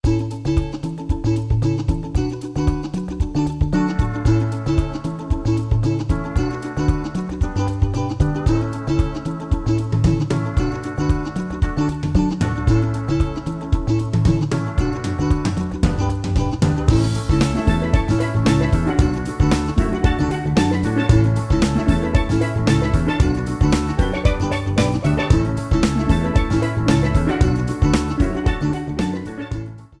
The music that plays on the title screen
This is a sample from a copyrighted musical recording.